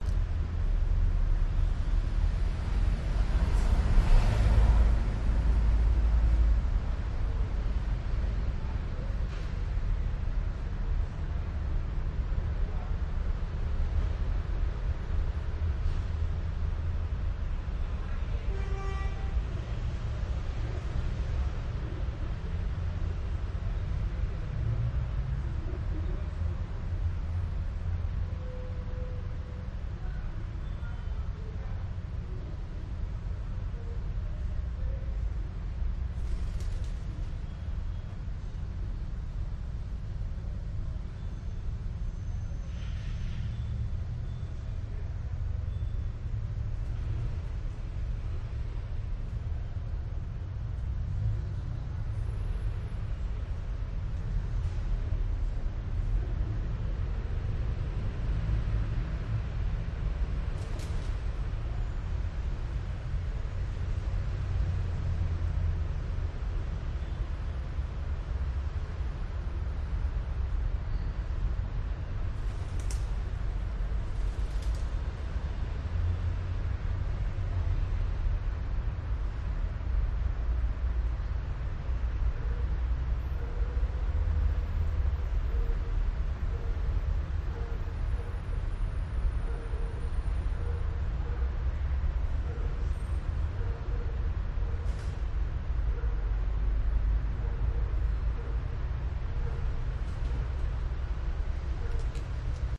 Звуки летнего вечера
Атмосферные звуки летнего вечера в городе (уличный шум)